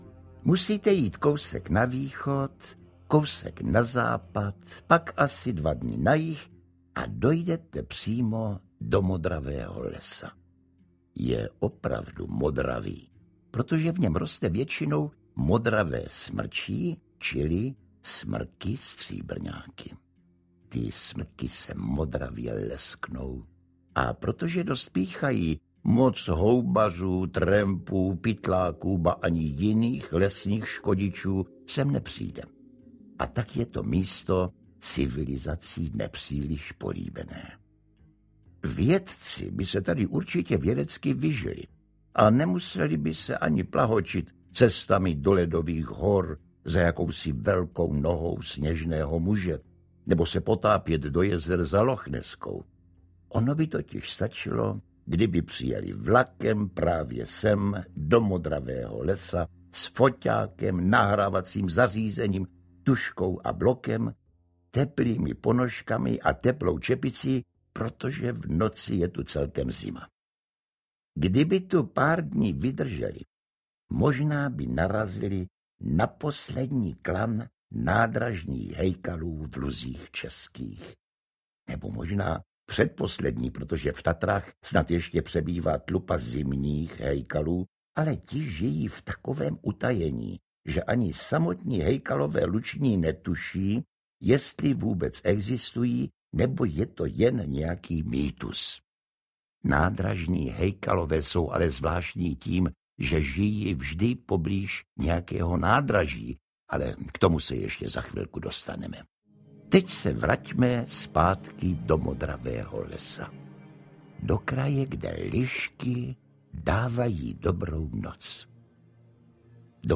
Hejkalov audiokniha
Ukázka z knihy
V podání pana Josefa Somra dostáváte do ruky napínavé dobrodružství nejen pro děti, ale i jejich rodiče.
• InterpretJosef Somr